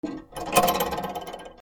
はかり
体重を量る ばねばかり 原音あり R26OM